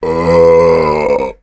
zombie_eat_1.ogg